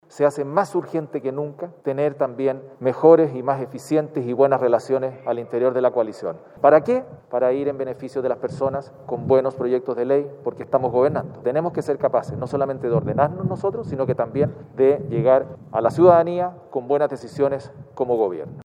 Tras las citas de hoy, Monckeberg señaló que como conglomerado deben ser capaces de generar un orden interno para así poder llegar de mejor forma a la ciudadanía.